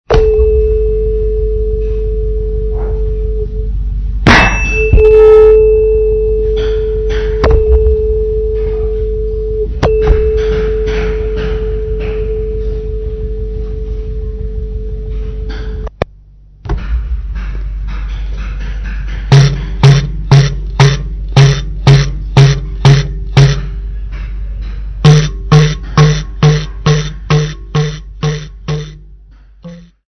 DD060-30.mp3 of Recording of Sitandi keys